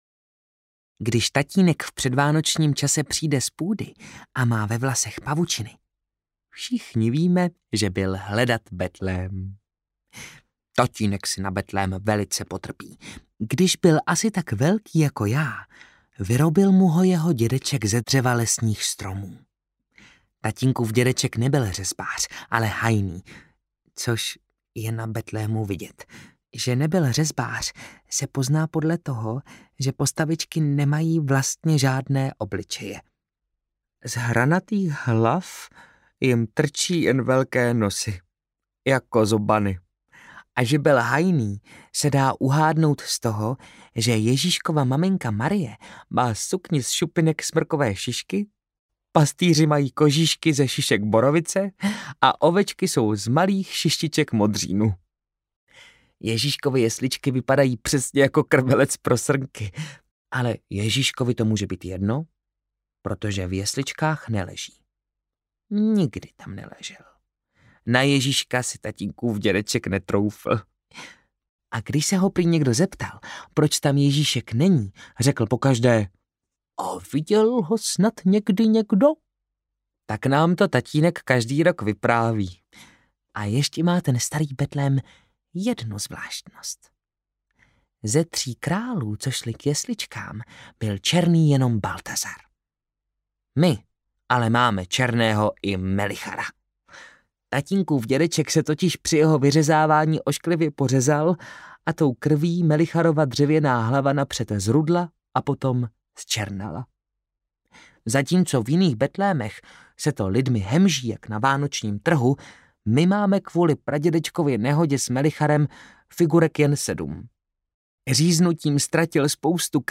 Mikuláš, Ježíšek, pan Vrána a my audiokniha
Ukázka z knihy